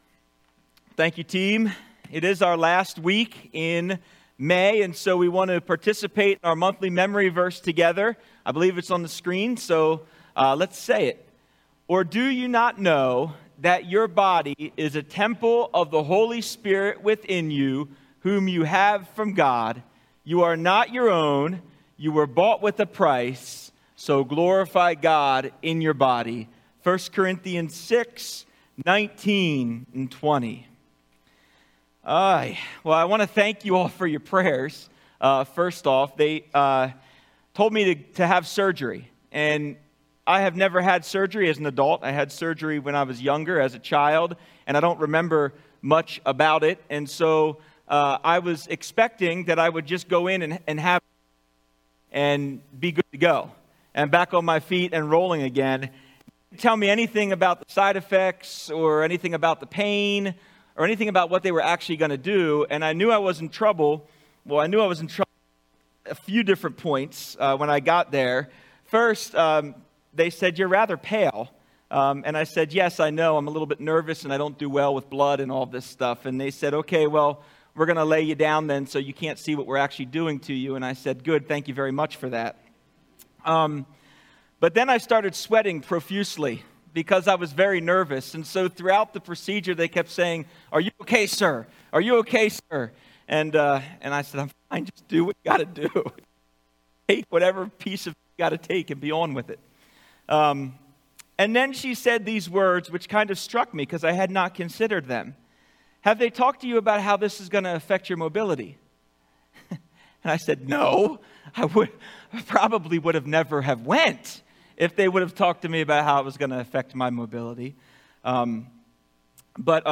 Sermons | Calvary Monument Bible Church